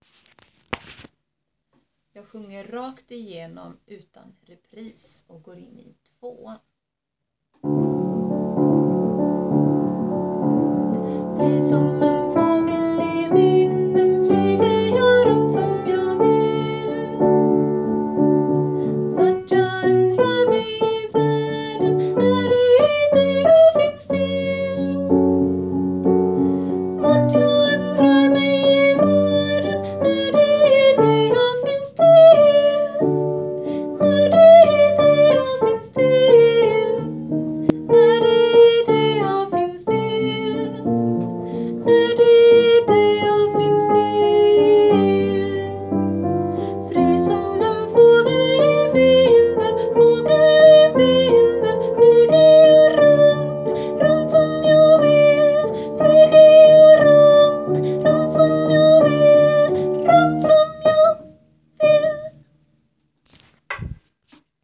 Fri som en fågel ten
fri som ten.wav